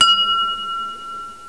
snd_25844_Bell.wav